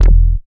70.06 BASS.wav